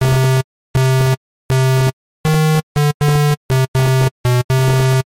8 бит версия